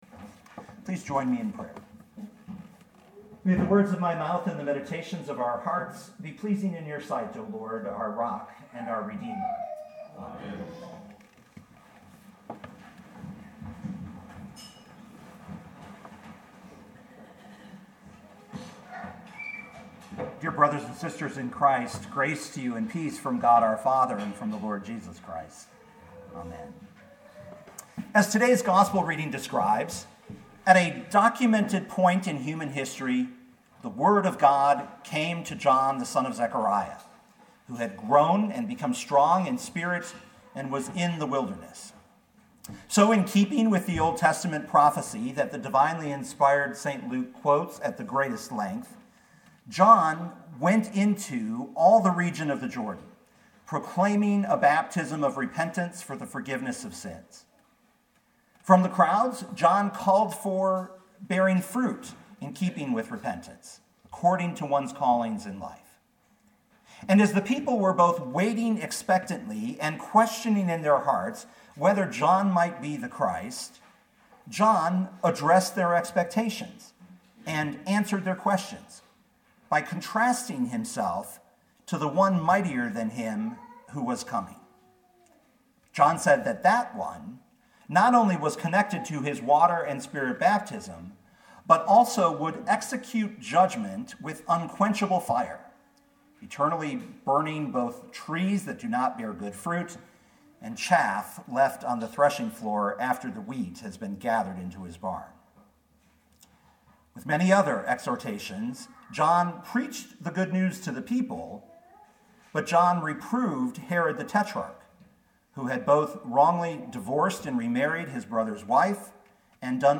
2018 Luke 3:1-20 Listen to the sermon with the player below, or, download the audio.